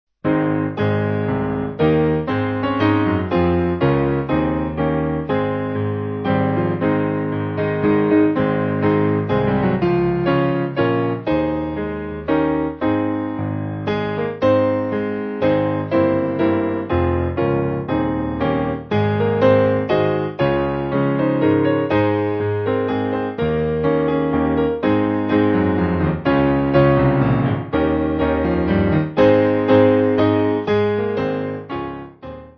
8.6.8.6 with refrain
Simple Piano